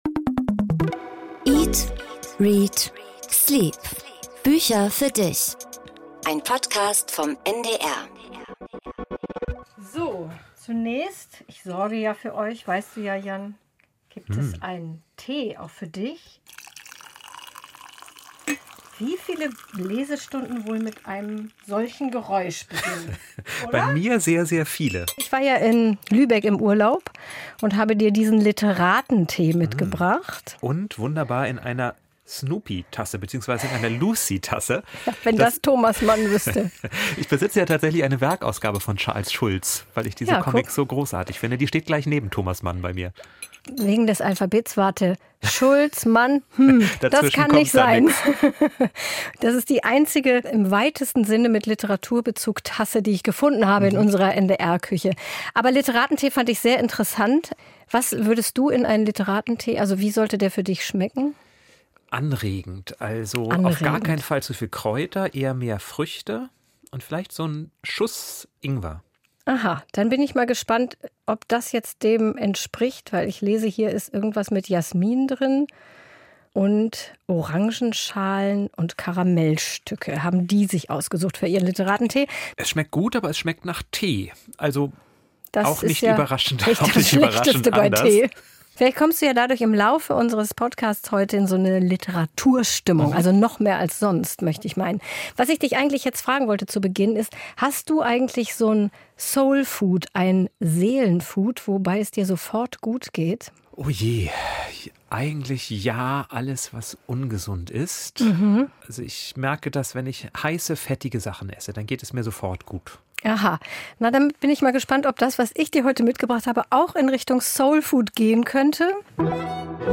Interview mit Frank Schätzing